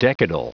Prononciation du mot decadal en anglais (fichier audio)
Prononciation du mot : decadal